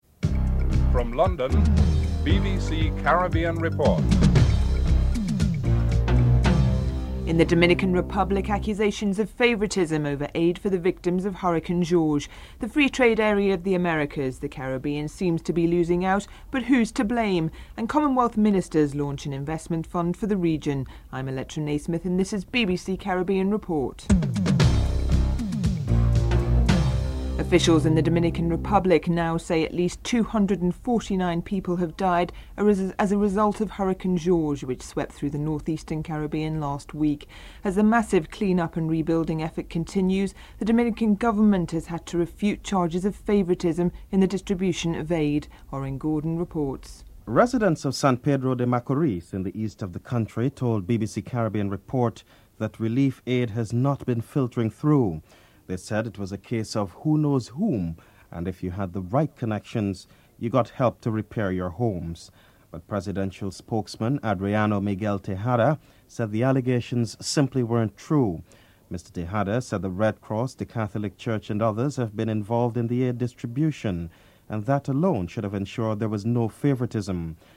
The British Broadcasting Corporation
World Bank President James Wilkinson is interviewed